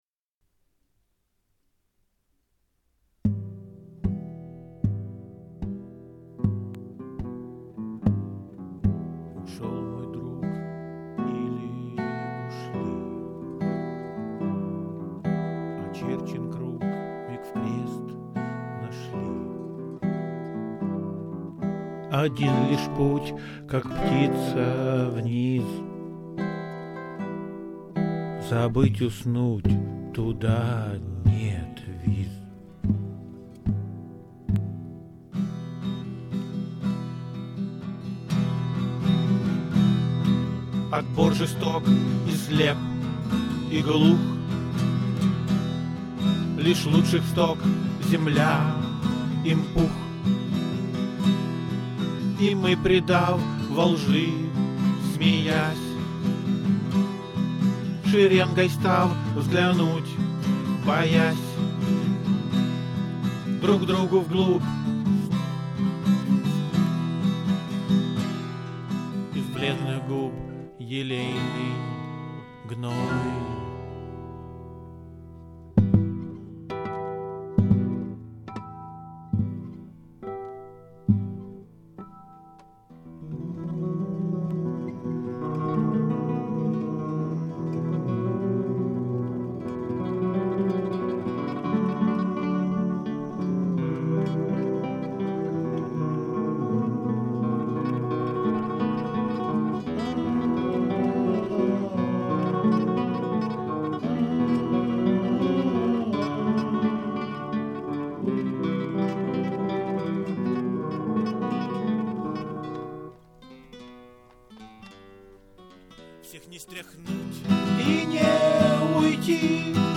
"Последний полет" - песня 44 tracks (слушать лучше в наушниках), lead guitars
музыка и rhythm guitar